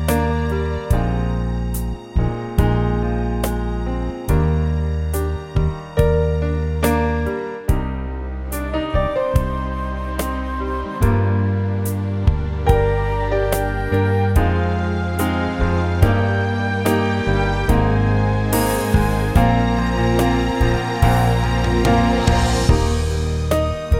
no Backing Vocals Easy Listening 3:13 Buy £1.50